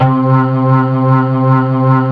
B3 TONE C3.wav